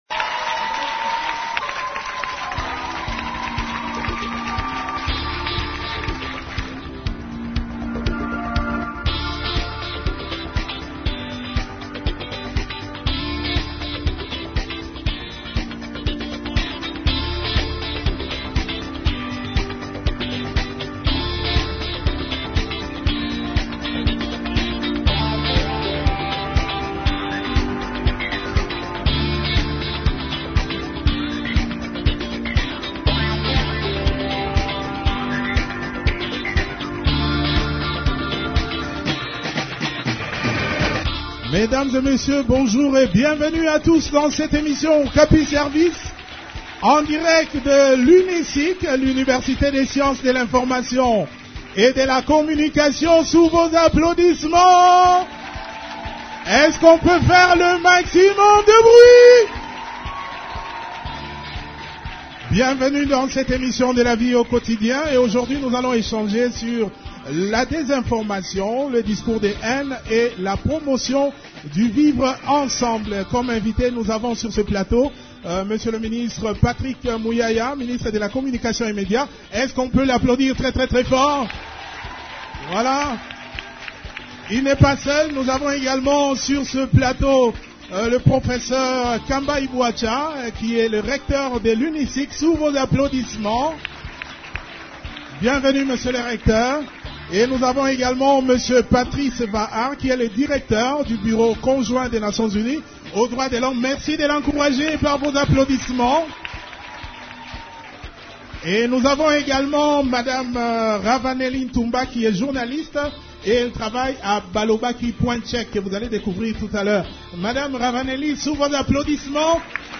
-Patrick Muyaya, ministre de la Communication et Médias